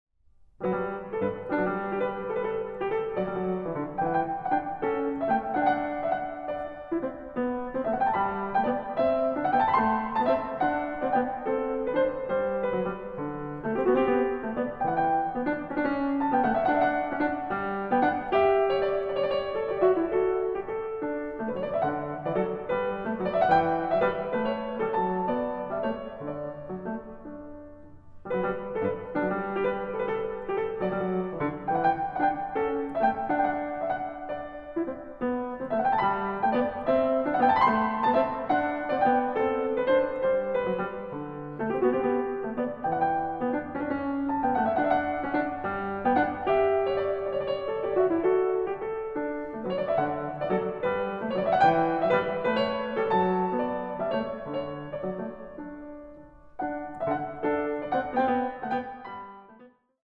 Pianistin